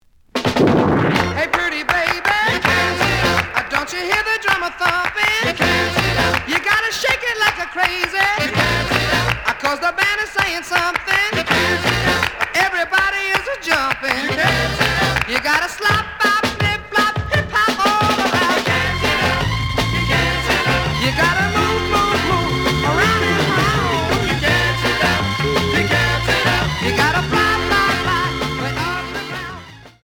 試聴は実際のレコードから録音しています。
●Genre: Rhythm And Blues / Rock 'n' Roll
●Record Grading: VG~VG+